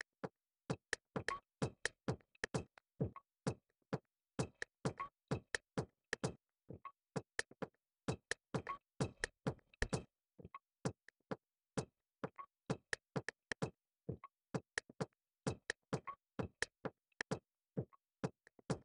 Sounds like a gated recording or something like that?
I dont know why this only happens on input channels 1 and 2 but I had “voice isolation” turned on for the microphone settings of the macbook, which caused this strange sound.